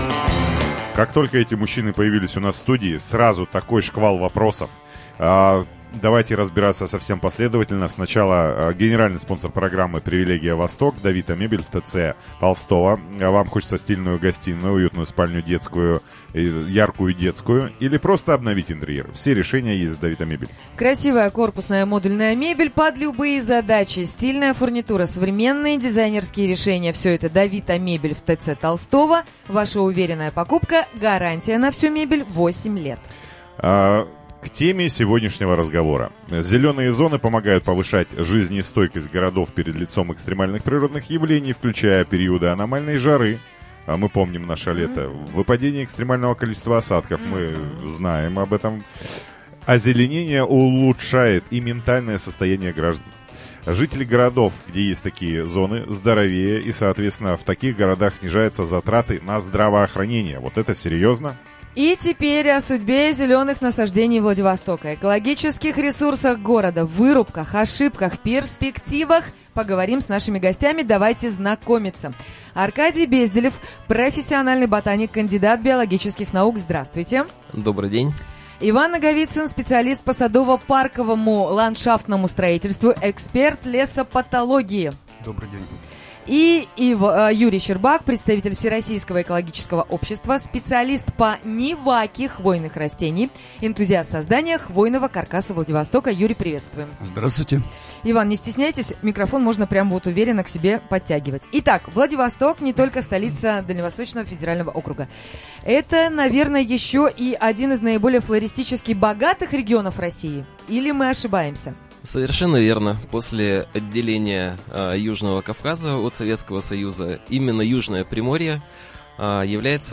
член Приморского отделения Российского экологического общества принял участие в прямом эфире на радио VBC на тему «Городские зеленые зоны».